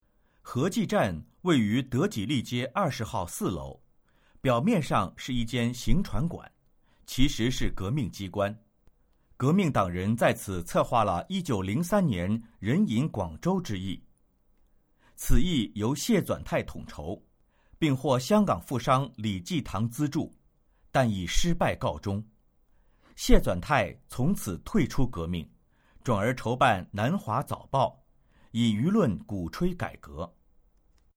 语音简介